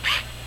再听听开启9是的声音：
是不是小多了，柔和多了，而且镜头也不象7有特别大的动作。